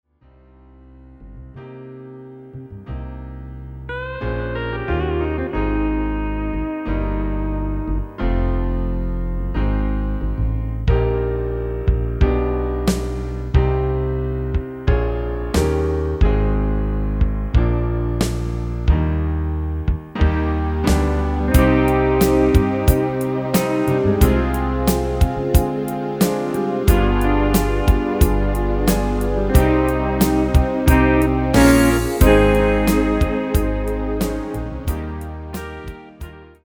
Demo/Koop midifile
Genre: Nederlandse artiesten pop / rock
Toonsoort: Cm
- Vocal harmony tracks
Demo's zijn eigen opnames van onze digitale arrangementen.